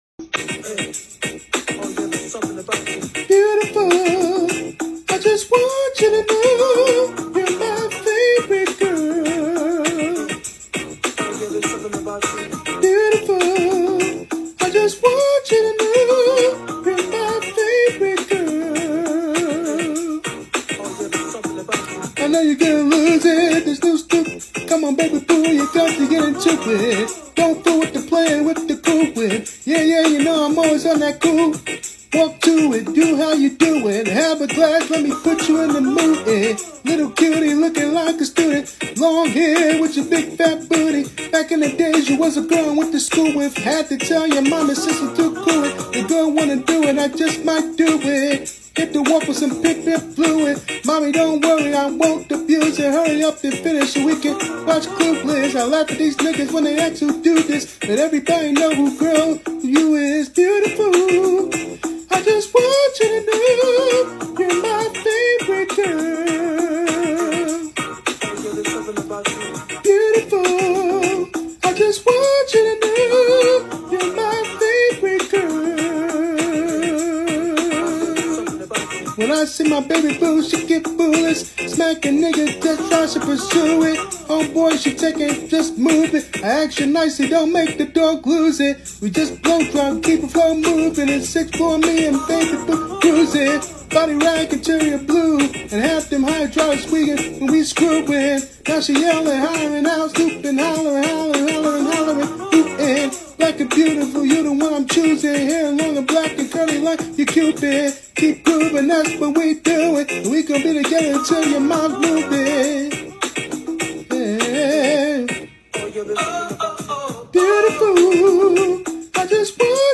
throwback cover